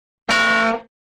Goofy Bonk
goofy-bonk.mp3